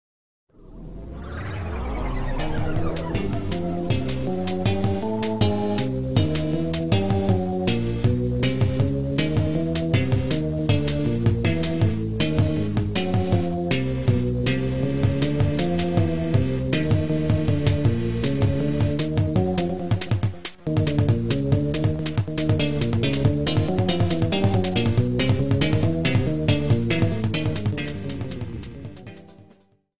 Reduced to 30 seconds, added fadeout.